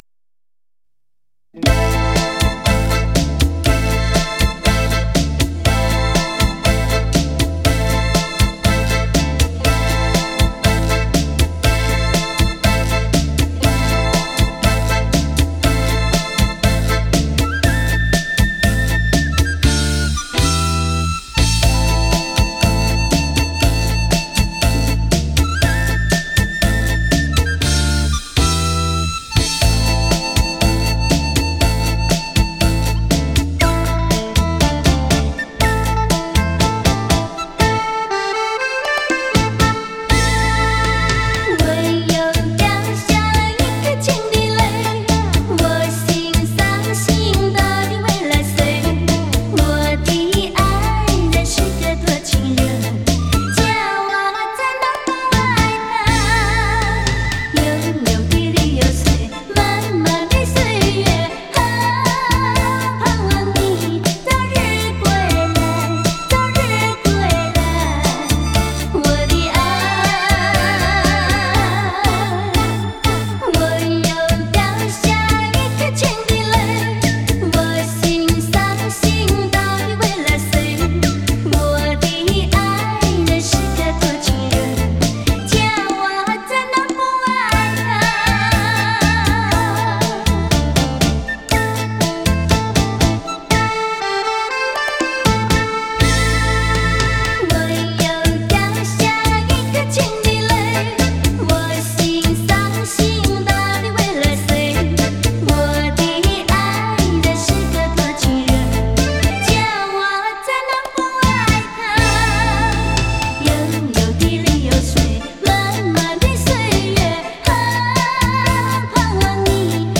发烧山地情歌